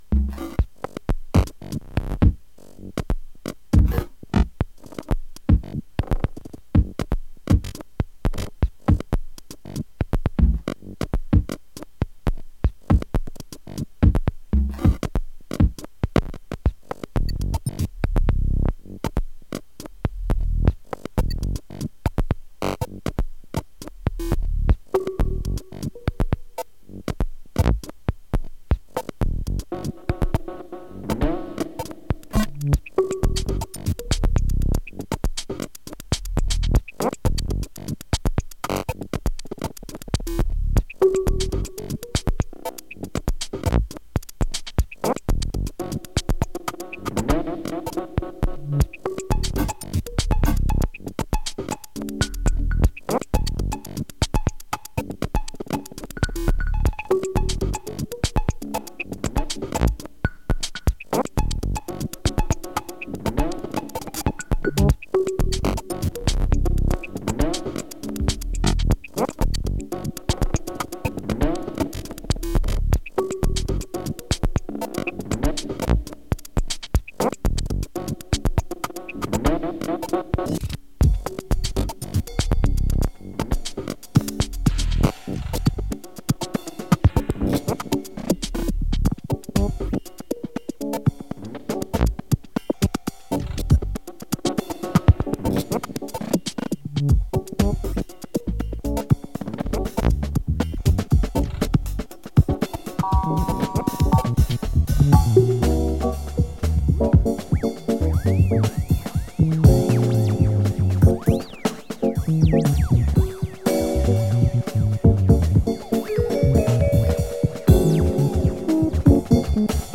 Tempo: 121 bpm